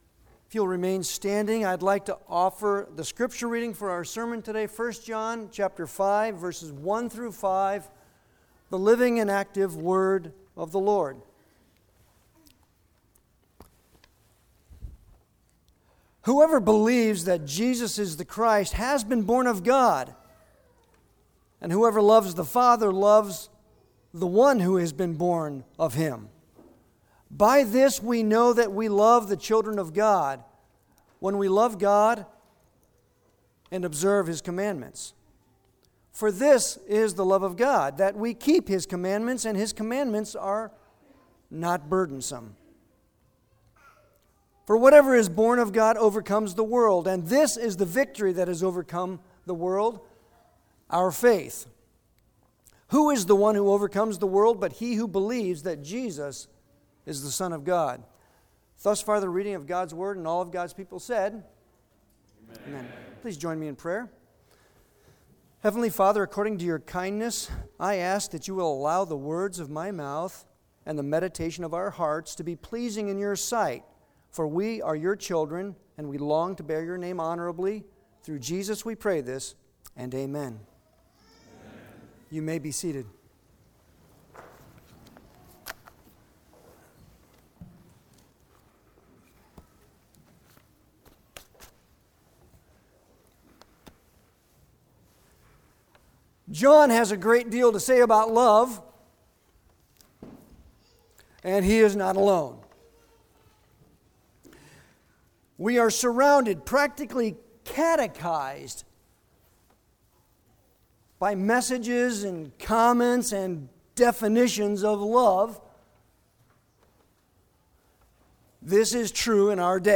Sermons on 1 John Passage: 1 John 5:1-5 Service Type: Sunday worship Download Files Bulletin « Love Others…Even Them?